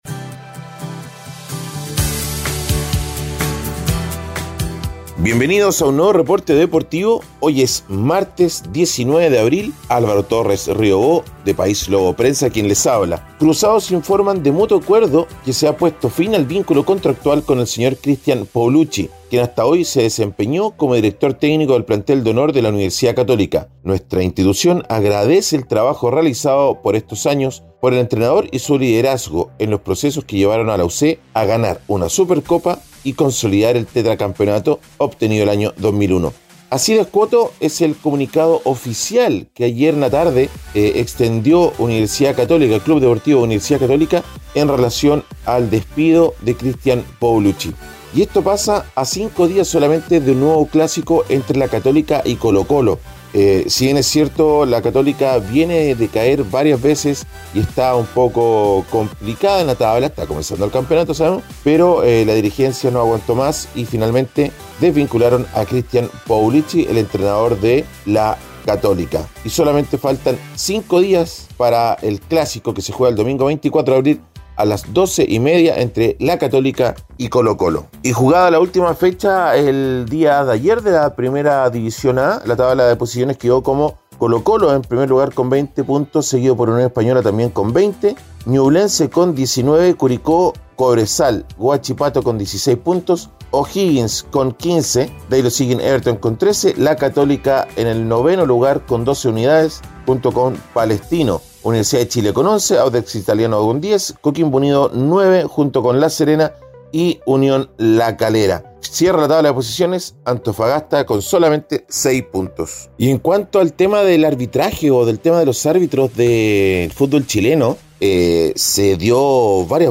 Reporte Deportivo ▶ Podcast 19 de abril de 2022